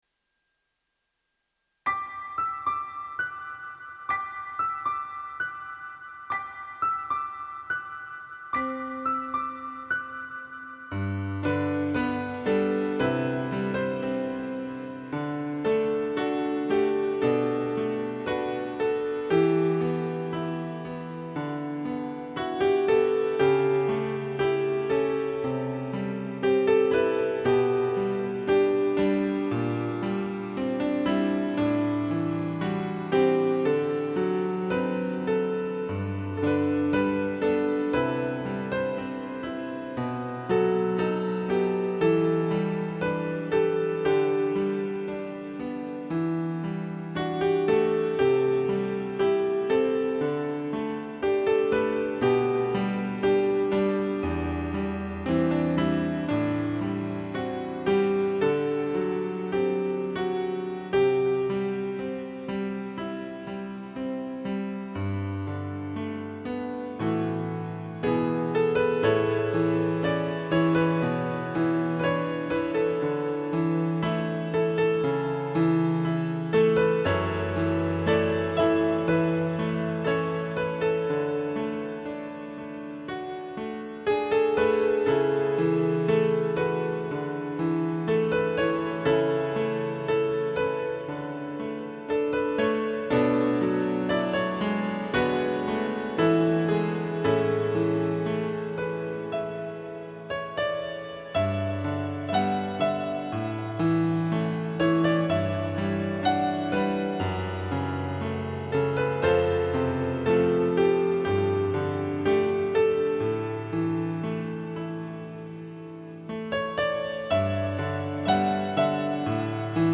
ギターのグリッサンド奏法をピアノでは装飾音で表現しています。